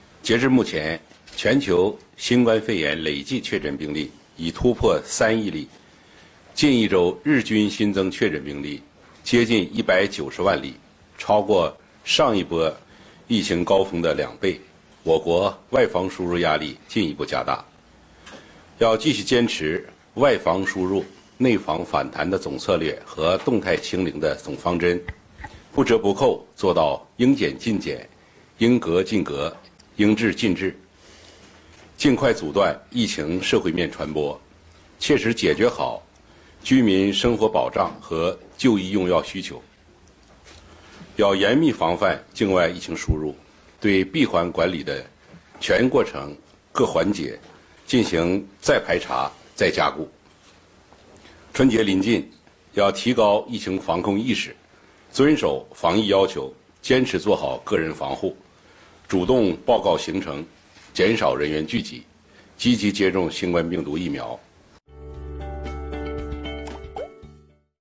新闻发布会现场
国家卫生健康委新闻发言人、宣传司副司长发布人米锋